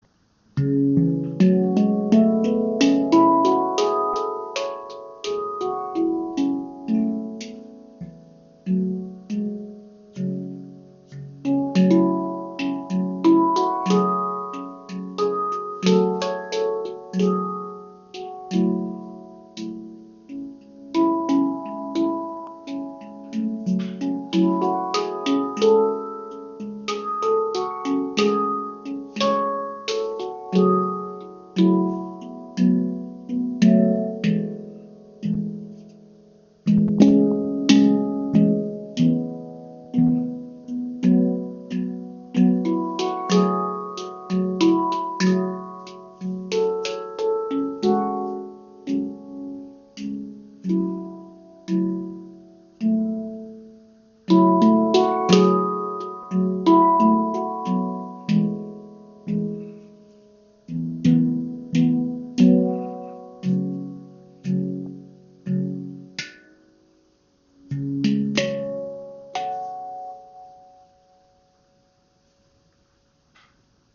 Handpan MAG | C# Pygmy 12
• Icon 12 Klangfelder ( 2 Bodennoten)
• Icon Handpan aus Ember Steel
Diese C# Pygmy Handpan mit 12 Klangfeldern hat auf der oberen Hälte 10 Töne und auf der unteren Seite 2 Bodennoten.